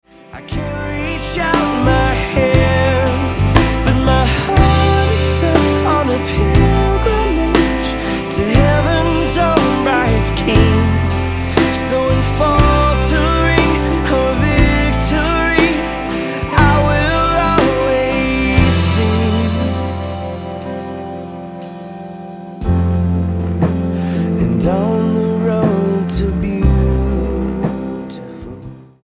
STYLE: Pop
slow tempo praise songs